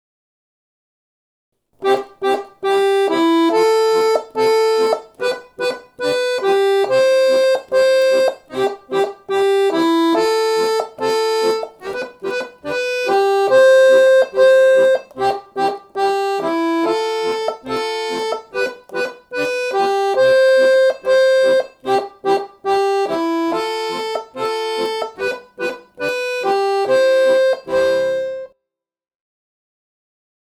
melo_y_acomp_parte_2.mp3